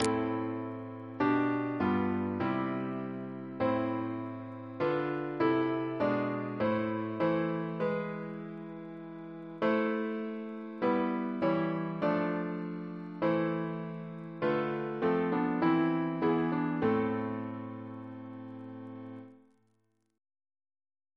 Double chant in A minor Composer: Jonathan Battishill (1738-1801) Reference psalters: ACB: 172; ACP: 137 154; CWP: 44; H1982: S179 S286; PP/SNCB: 136; RSCM: 18